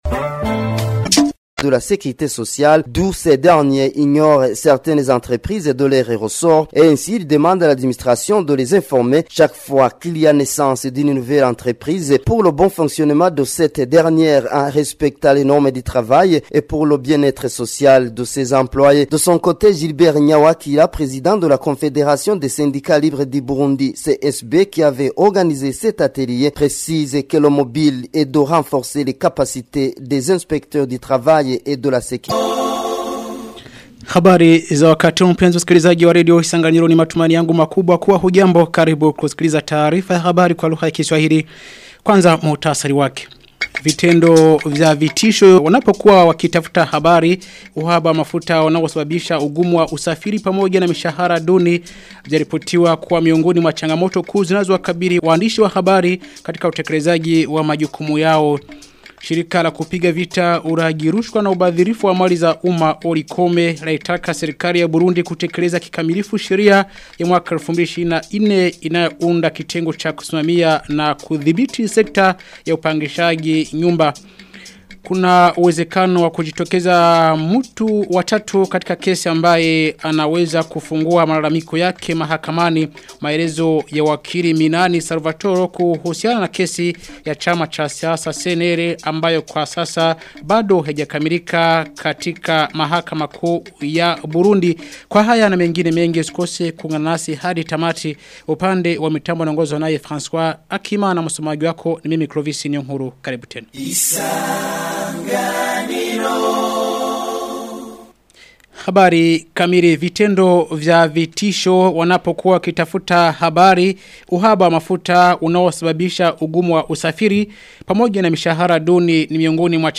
Taarifa ya habari ya tarehe 10 Februari 2026